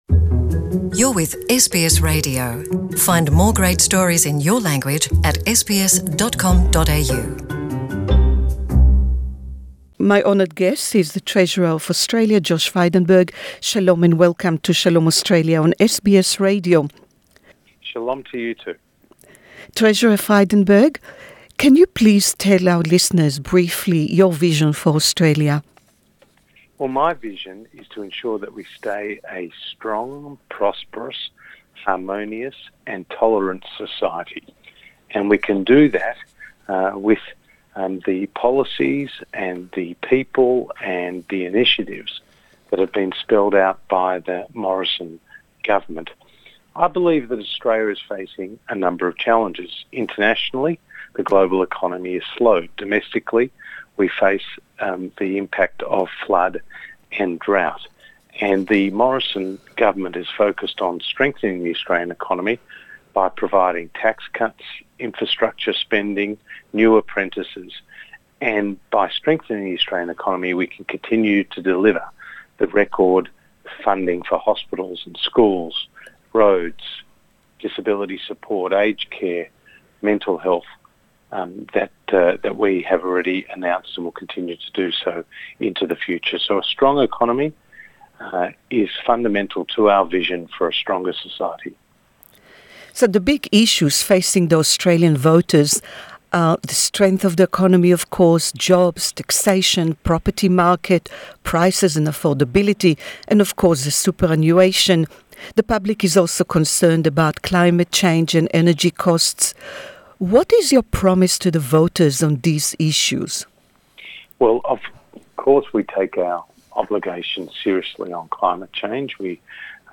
Treasurer Josh Frydenberg in conversation